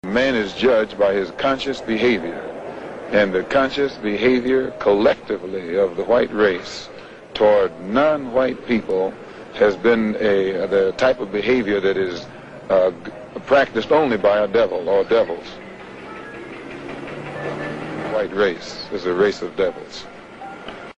Malcolm X is interviewed about his political position !